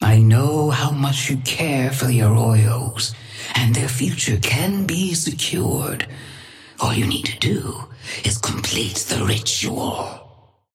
Sapphire Flame voice line - I know how much you care for the Arroyos, and their future can be secured.
Patron_female_ally_tengu_start_03.mp3